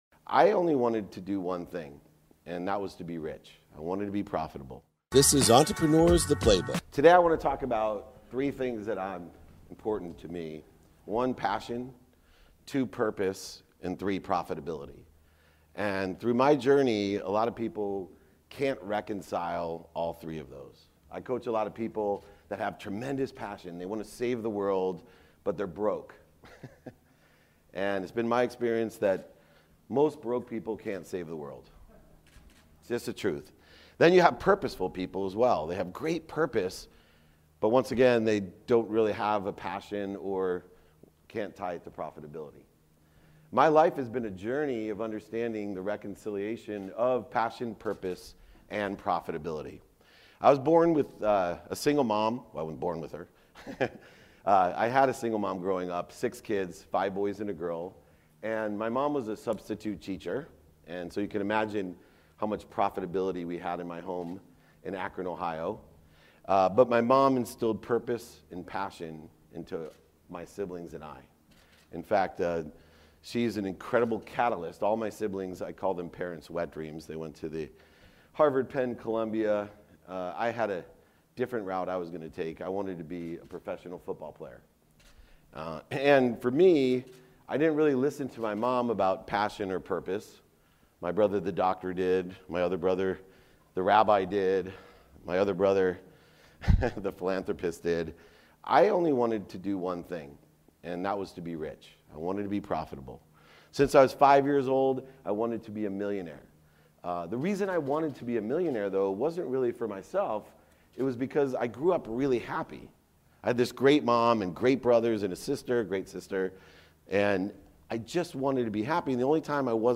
This is a quick 10 minute keynote I gave at UCI last year. After living a life profitability, this is the story of finding my passion and purpose.